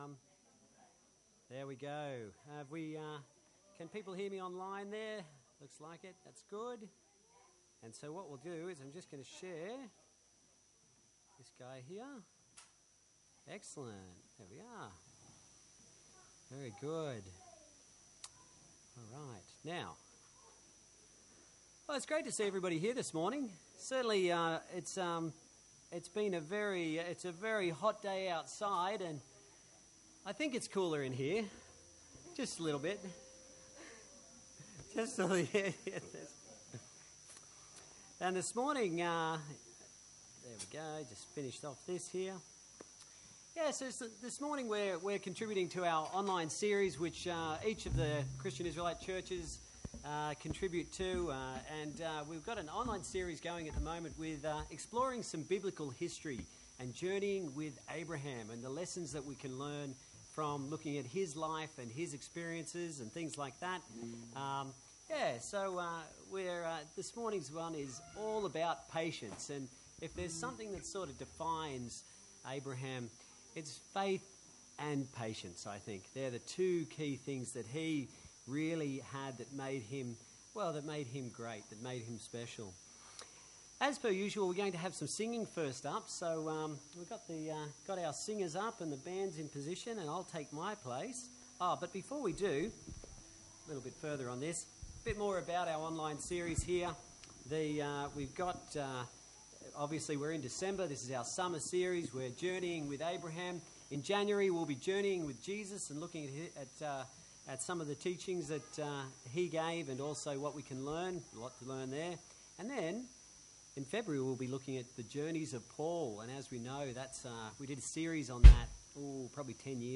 Genesis 16 Service Type: Sunday Church God’s promises are sure